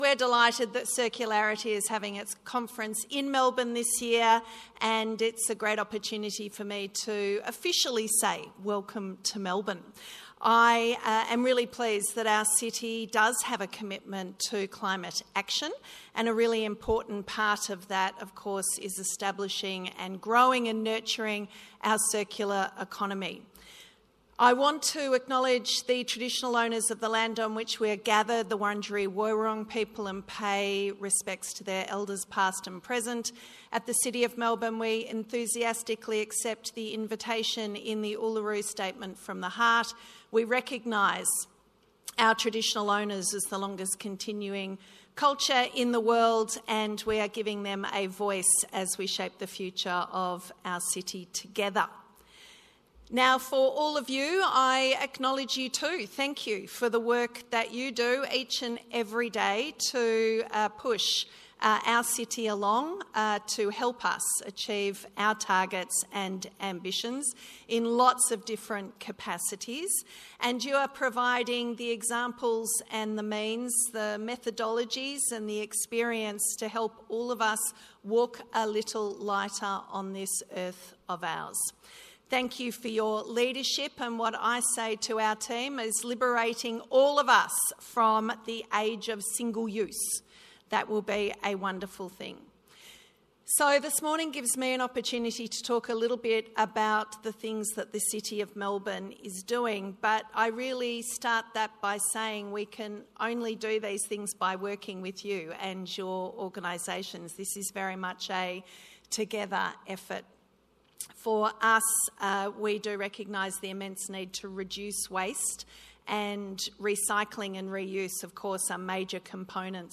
Keynote Address by Lord Mayor, Sally Capp - Circularity Live
Savoy-Day-2-Keynote-Address-by-Lord-Mayor-Sally-Capp-NR.mp3